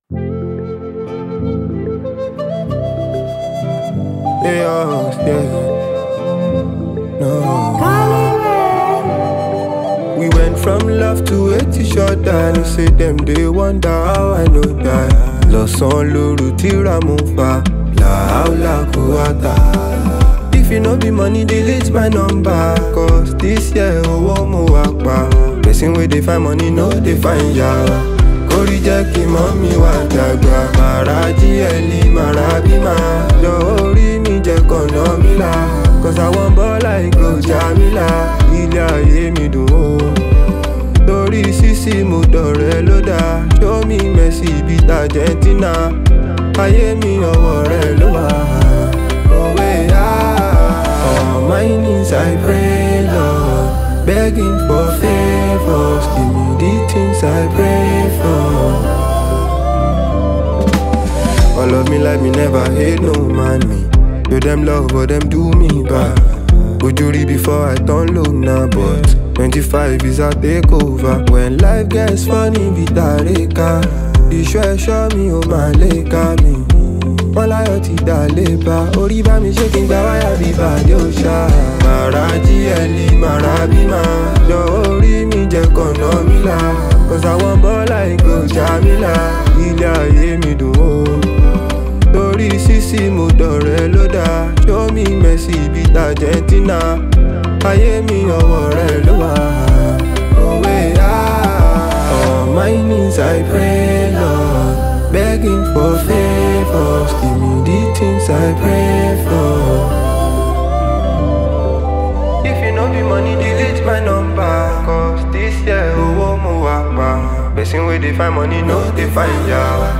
Nigeria talented Afrobeats music singer and songwriter